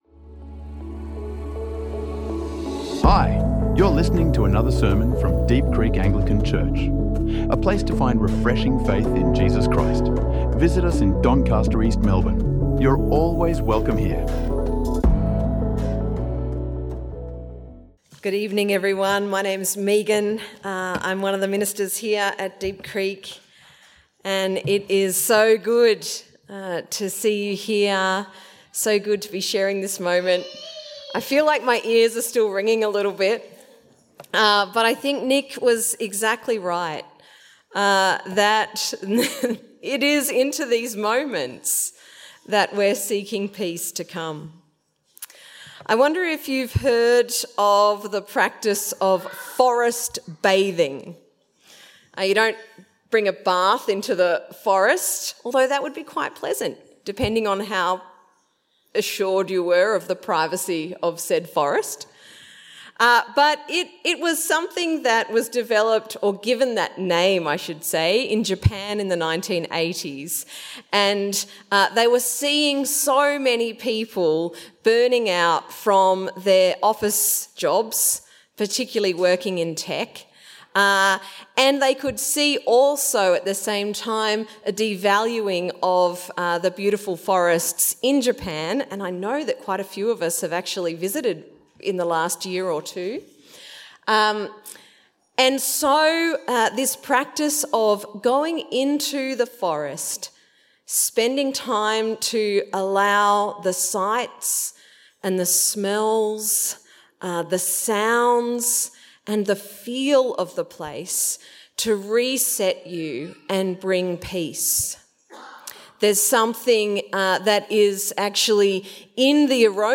The Path to Peace | Sermons | Deep Creek Anglican Church